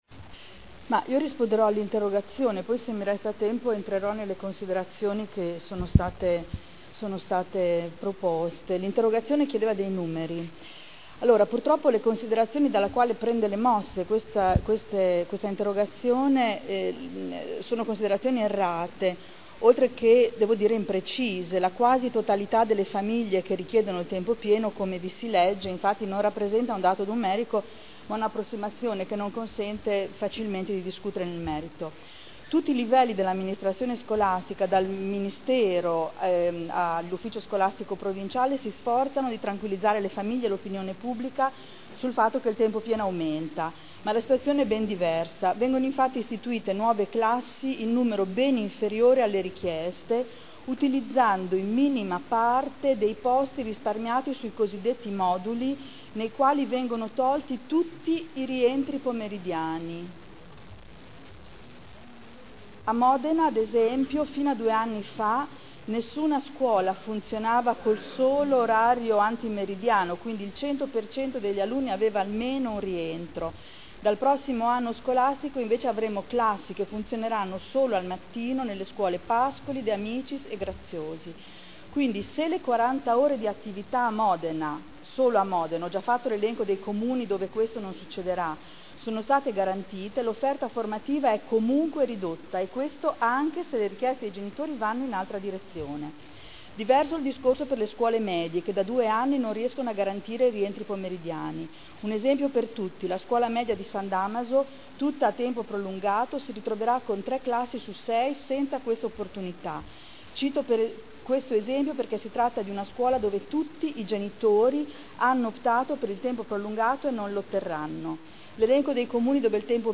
Adriana Querzè — Sito Audio Consiglio Comunale